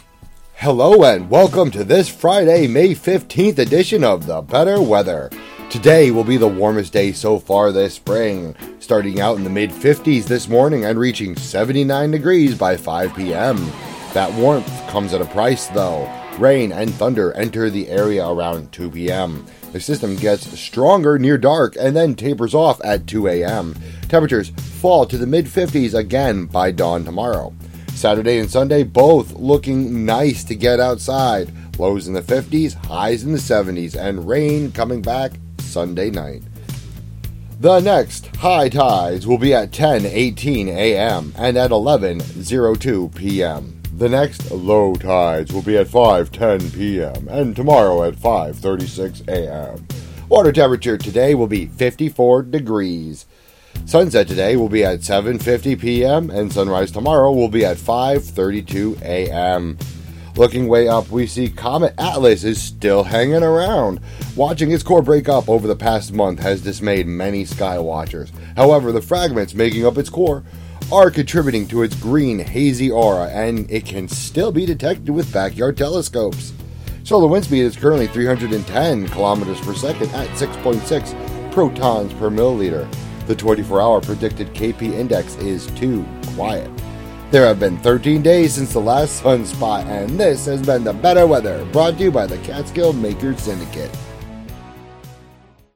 Today's local weather.